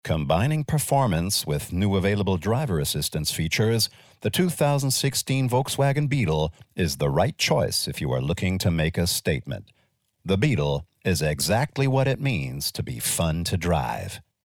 US English (Werbung) VW Beetle